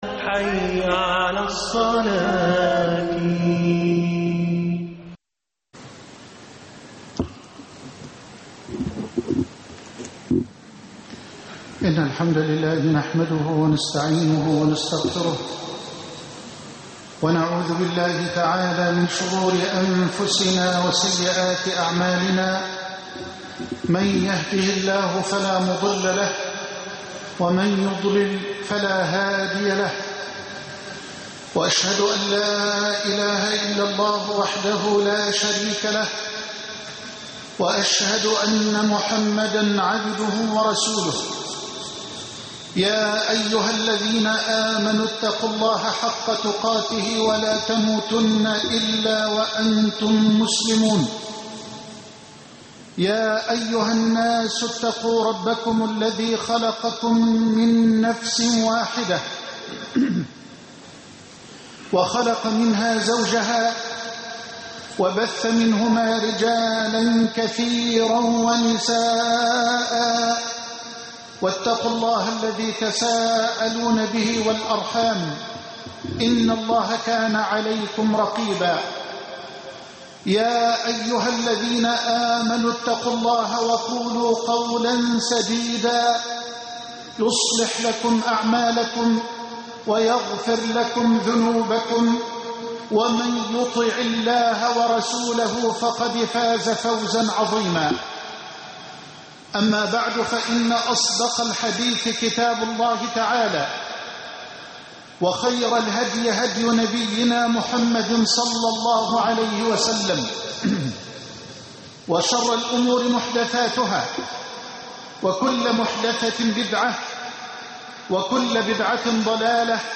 بورسعيد تاريخ مجيد(16-3-12) خطب الجمعة - فضيلة الشيخ محمد حسان